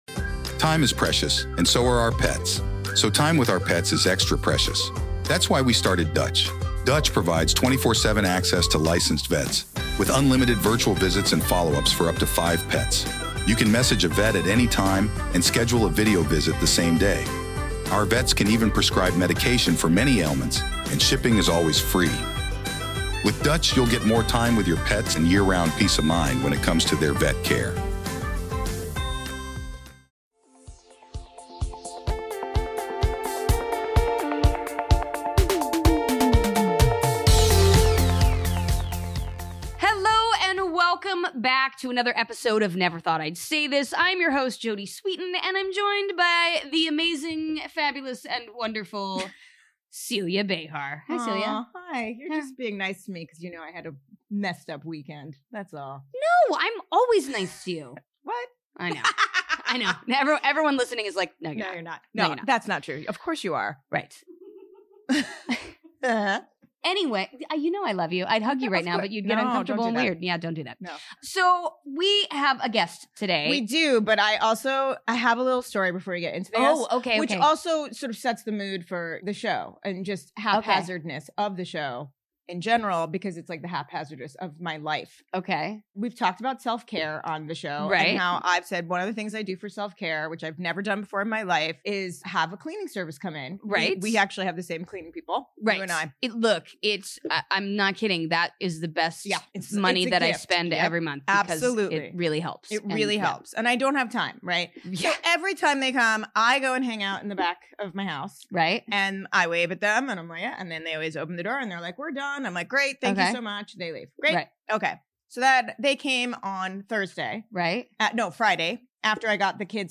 This week, the ladies are joined by award-winning freelance journalist and author